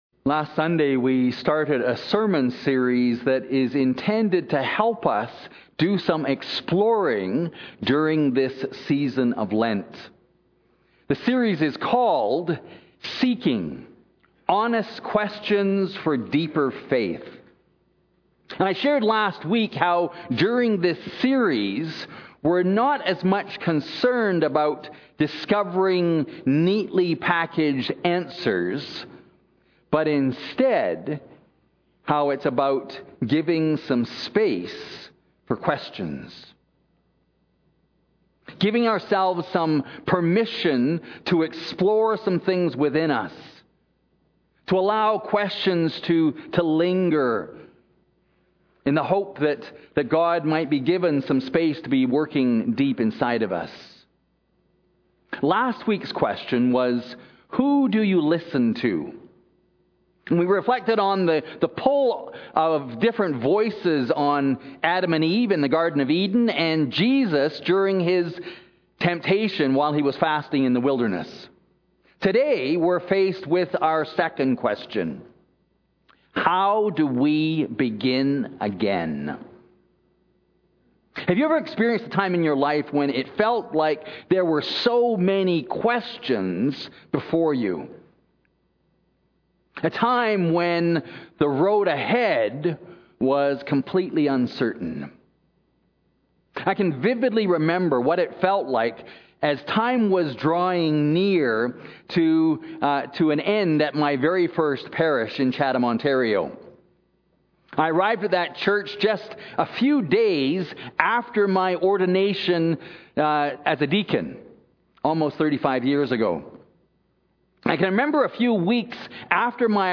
- Holy Trinity Anglican Church (Calgary)